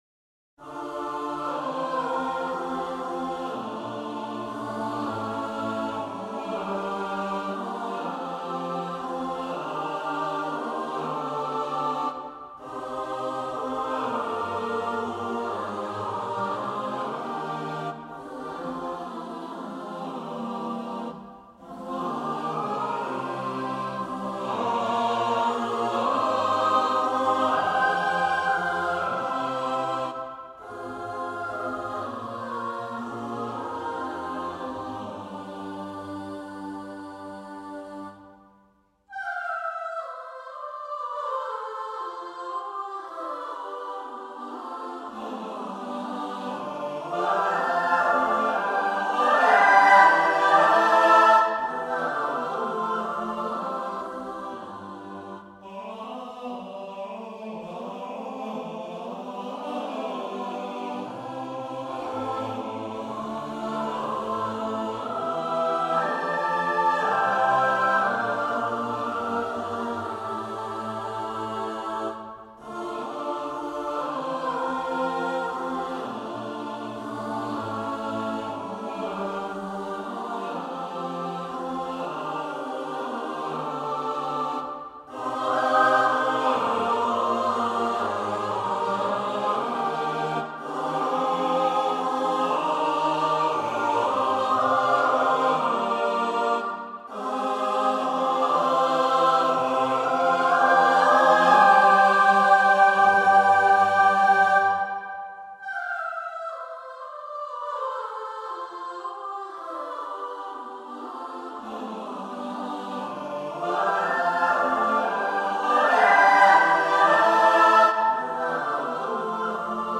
Number of voices: 6vv Voicing: SSAATB Genre: Secular, Motet
Language: English Instruments: A cappella